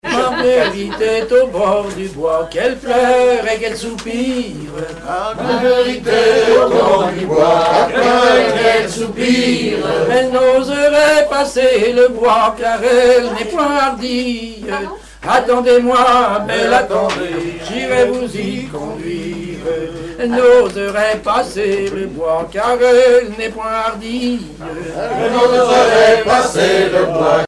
danse : ronde à trois pas
Genre laisse
Chansons et commentaires
Catégorie Pièce musicale inédite